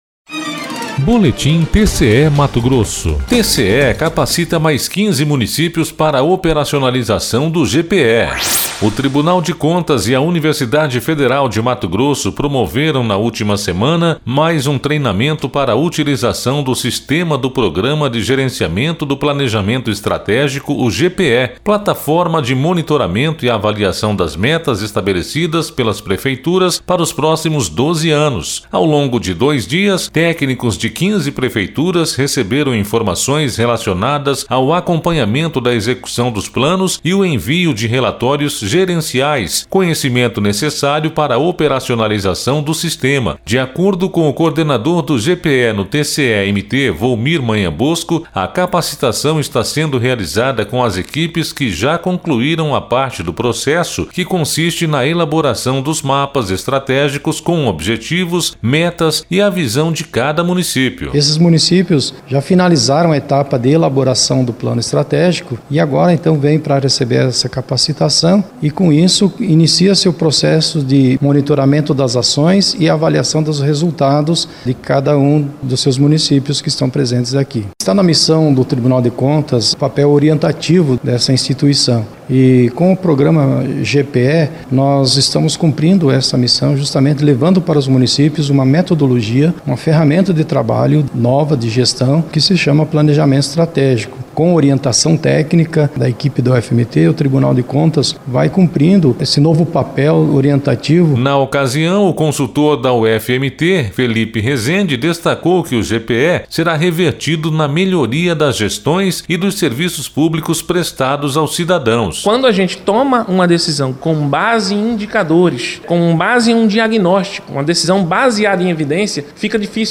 Sonora: Jeffer Kleber - vice-prefeito de Mirassol D’Oeste